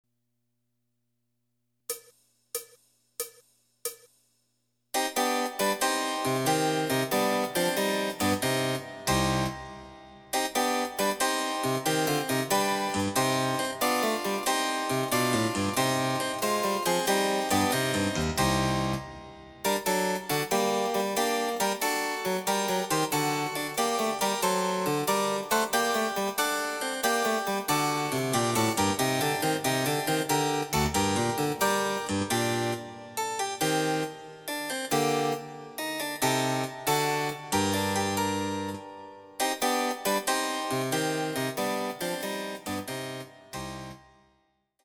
★フルートの名曲をチェンバロ伴奏つきで演奏できる、「チェンバロ伴奏ＣＤつき楽譜」です。
試聴ファイル（伴奏）